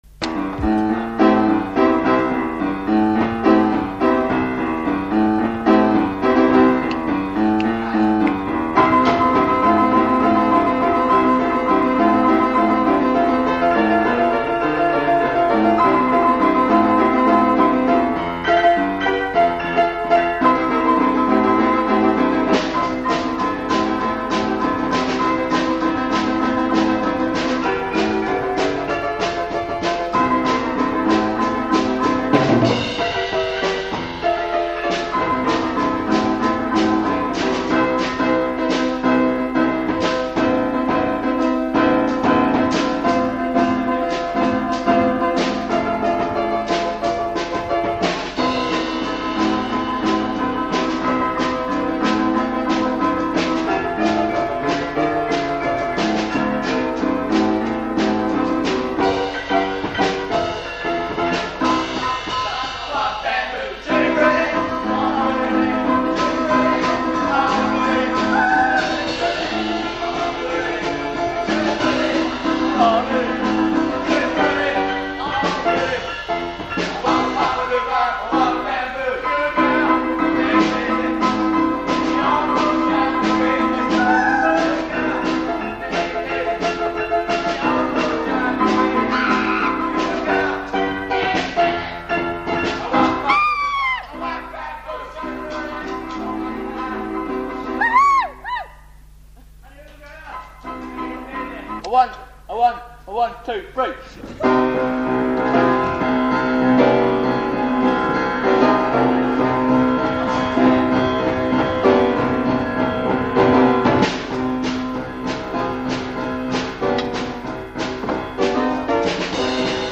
In these recordings, notice that there are never more than 3 instruments (including voice), cos the rules limited us to 3 people max.
Poynter Boys Jammin' (what we did after the Against All Odds rehearsal got interrupted... :))